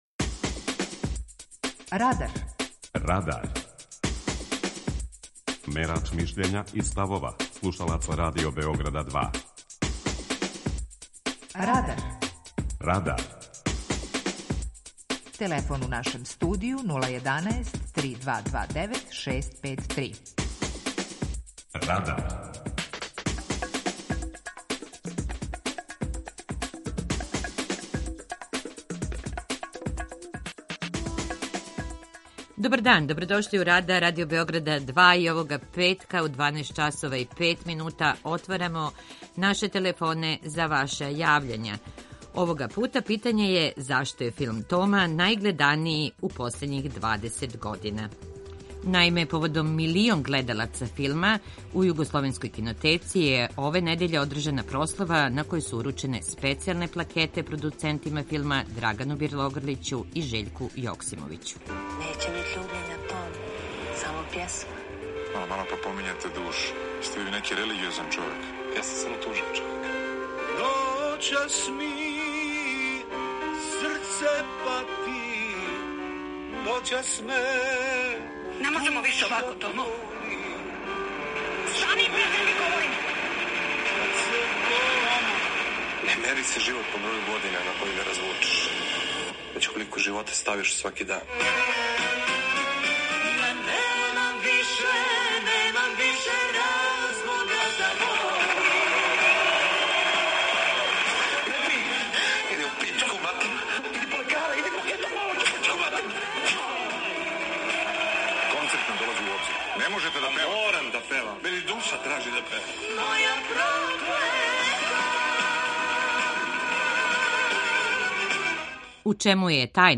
Питање Радара је: Зашто је „Тома” најгледанији филм у последњих 20 година? преузми : 19.27 MB Радар Autor: Група аутора У емисији „Радар", гости и слушаоци разговарају о актуелним темама из друштвеног и културног живота.